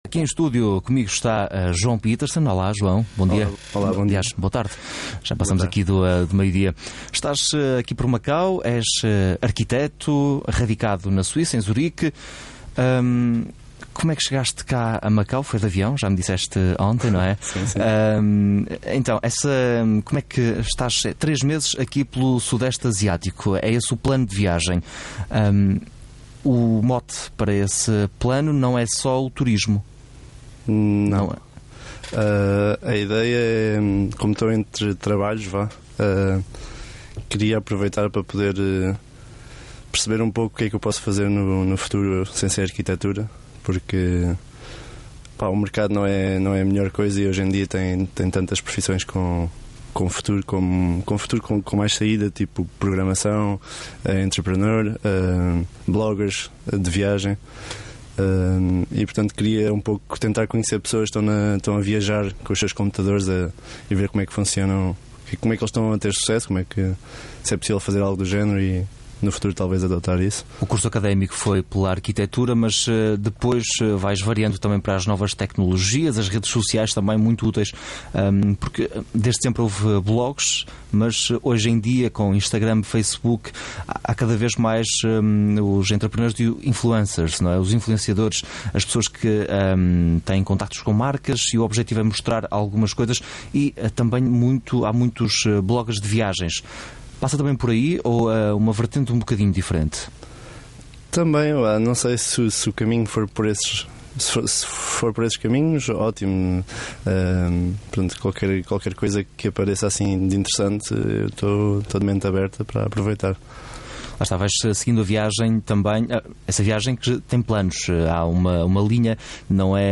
interview for radio macau